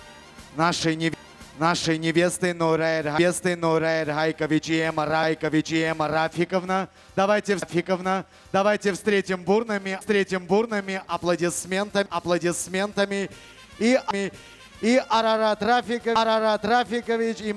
прошу помогите, ситуация патовая, случайно стер с рекордера звук, восстановил, но звук - 10 сек идет норм, потом повтор фрагмента на 5 сек из того, что было ранее, я так понимаю потом идет опять нормальные 10 сек.. и повторяется цикл... конечно вручную можно собрать, но это просто будет ад...